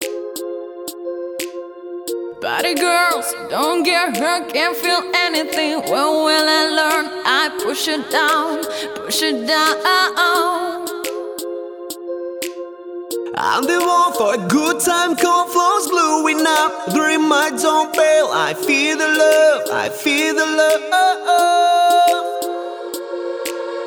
поп
спокойные
Cover
красивый мужской вокал
красивый женский вокал